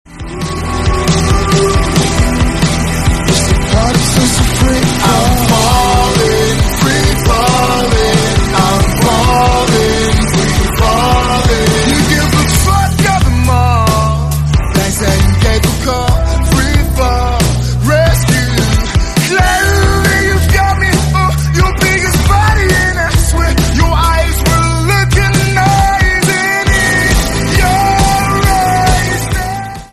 free-fall-3.mp3